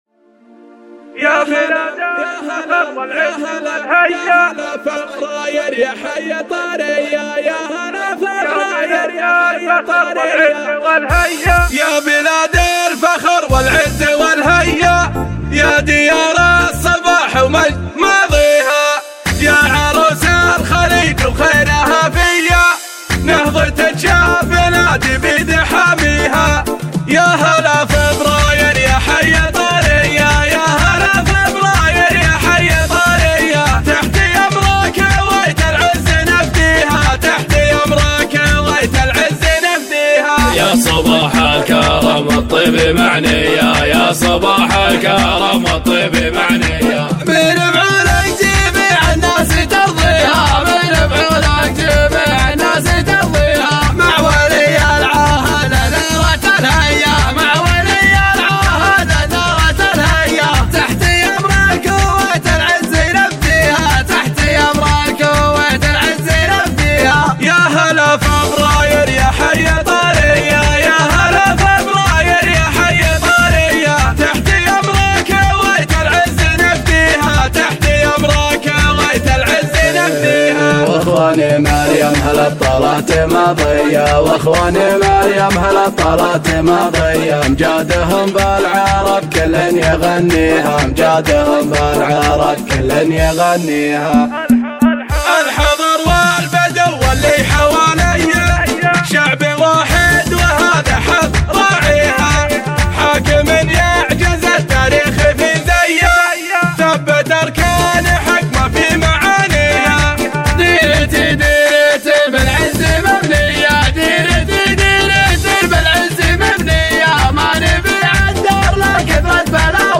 شيلة (وطنية)